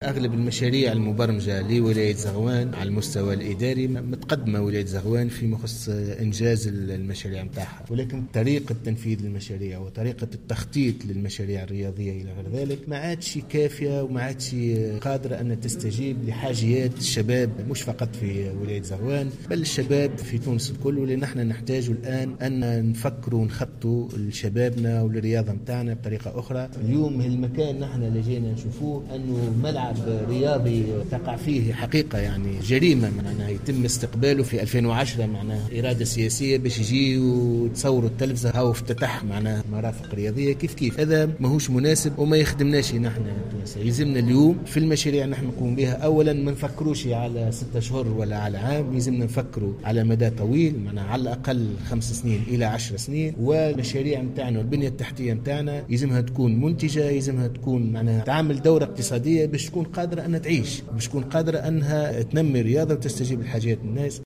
وقال قعلول في تصريح لمراسلة "الجوهرة أف أم" بالجهة إن هذه الزيارة تندرج في إطار متابعة المشاريع الرياضية المبرمجة بالجهة، مؤكدا تسجيل تقدم هام في إنجازها.